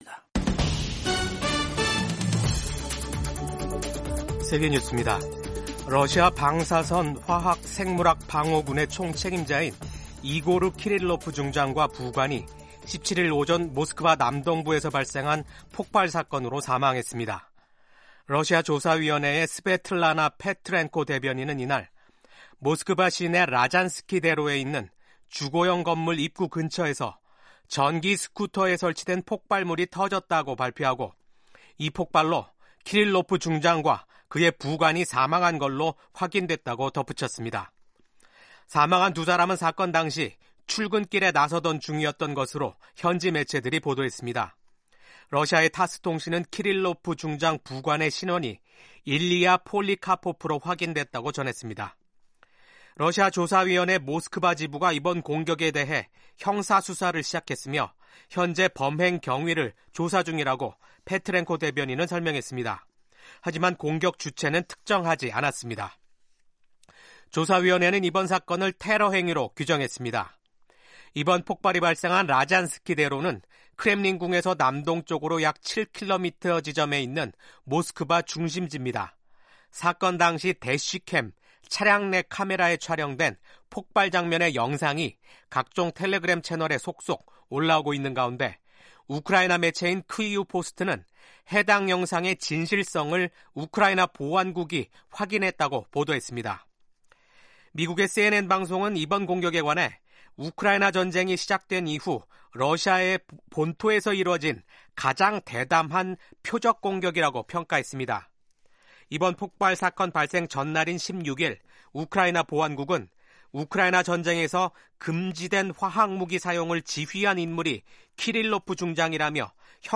세계 뉴스와 함께 미국의 모든 것을 소개하는 '생방송 여기는 워싱턴입니다', 2024년 12월 18일 아침 방송입니다. 도널드 트럼프 미국 대통령 당선인이 대선 승리 후 첫 기자회견을 갖고 관세부터 우크라이나 전쟁, 백신 접종에 이르기까지 광범위한 국내외 현안에 관한 견해를 밝혔습니다. 올라프 숄츠 독일 총리가 연방 의회에서 불신임되면서 실각 위기에 놓였습니다.